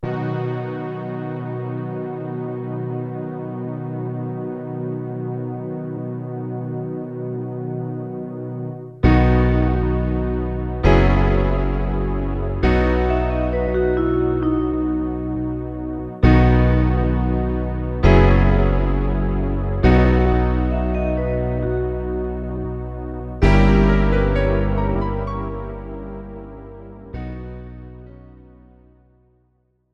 Without Backing Vocals. Professional Karaoke Backing Tracks.
This is an instrumental backing track cover.
Key – B
No Fade